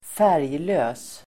Uttal: [²f'är:jlö:s]